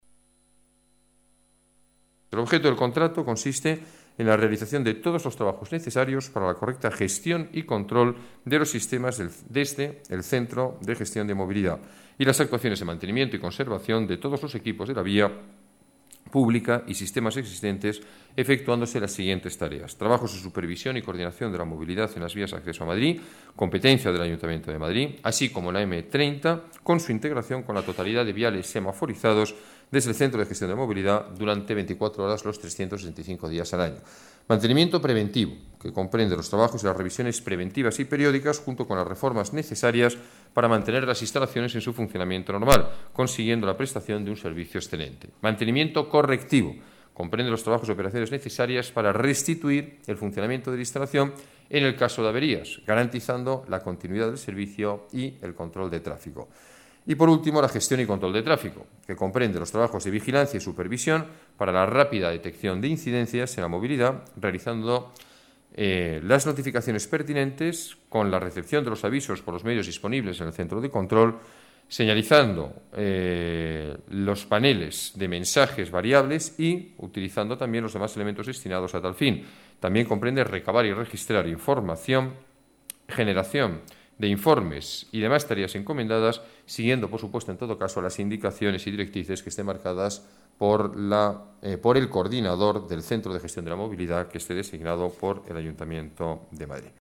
Nueva ventana:Declaraciones del alcalde, Alberto Ruiz-Gallardón: Mantenimiento M-30